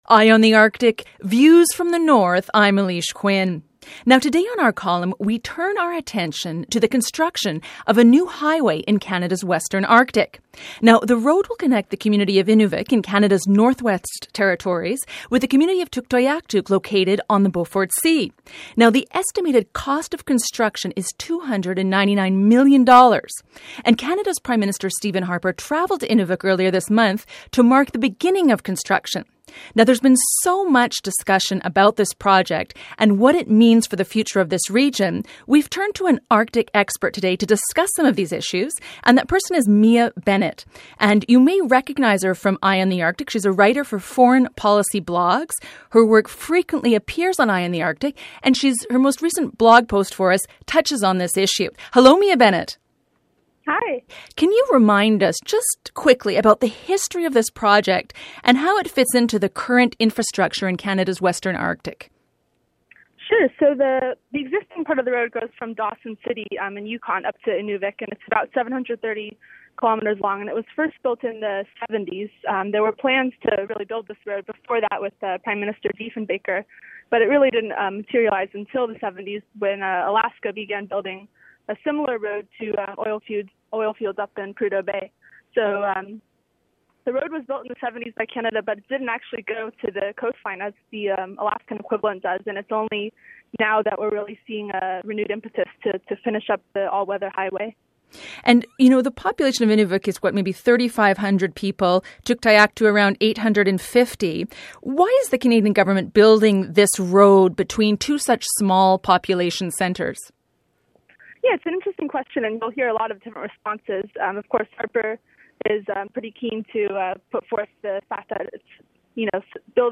Feature Interview: What Arctic highway project tells us about Canada’s plans for North